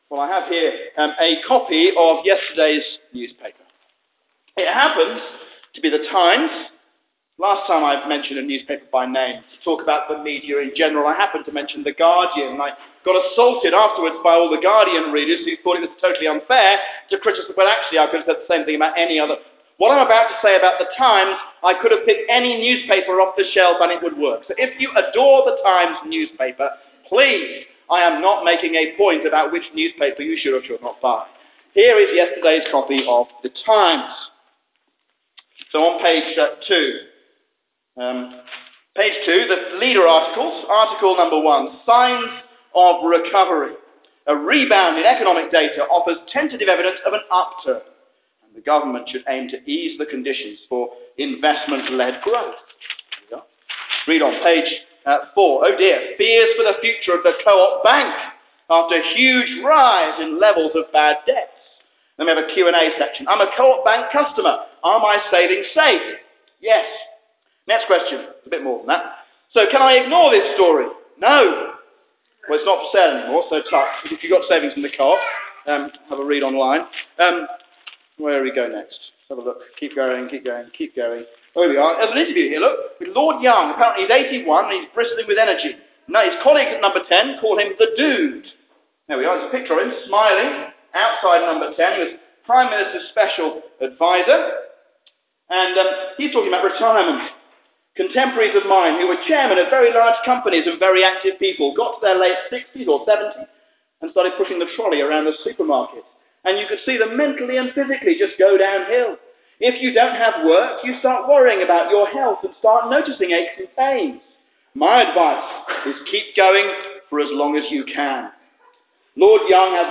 A sermon on 1 John 2:13-15